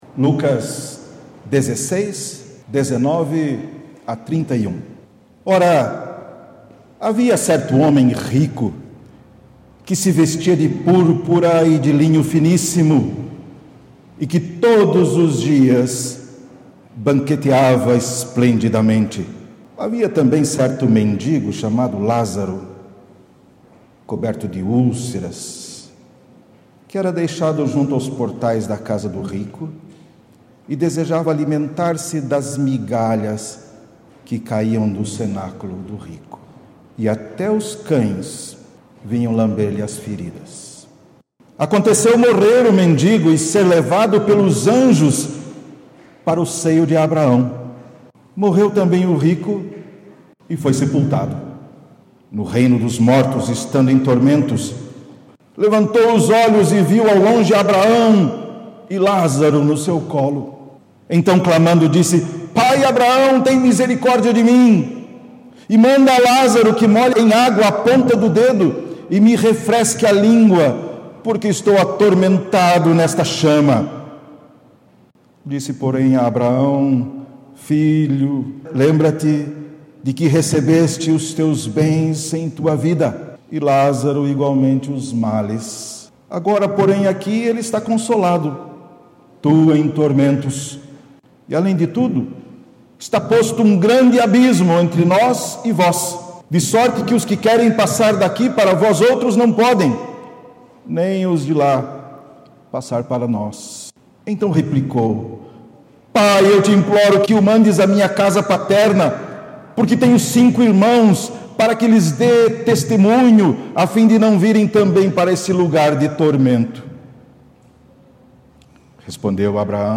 Prédica gravada na Igreja Metodista em Pirassununga – 30.09.2019